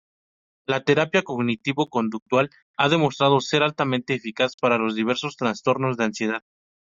Pronounced as (IPA) /koɡniˈtibo/